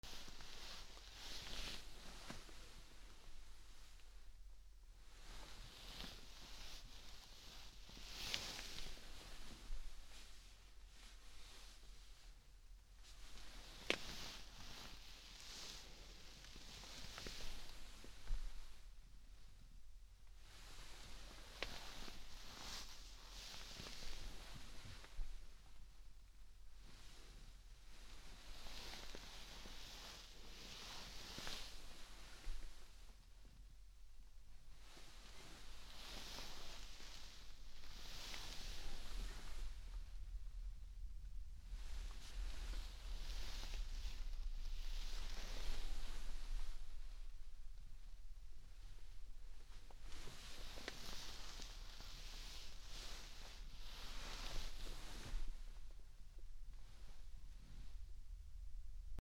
/ M｜他分類 / L45 ｜年齢制限コンテンツ / 布ずれ
布ずれ08 センター→しゃがみ